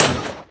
sounds / mob / blaze / hit3.ogg
hit3.ogg